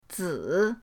zi3.mp3